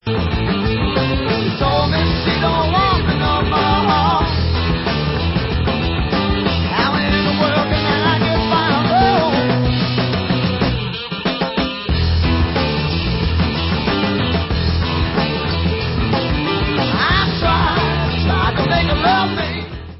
sledovat novinky v oddělení Blues